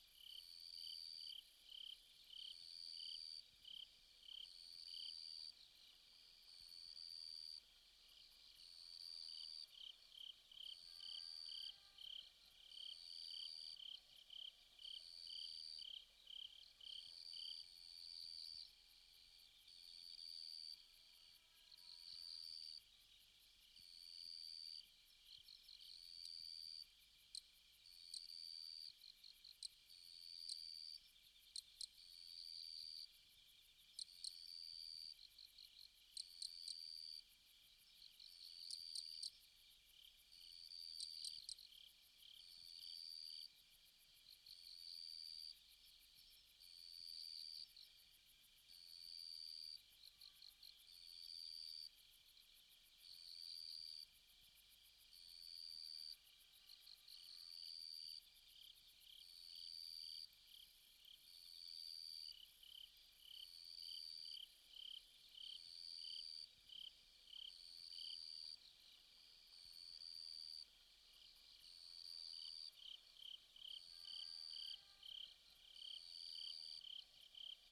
sfx_夜晚虫鸣.wav